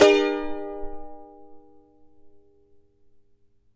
CHAR A MN  D.wav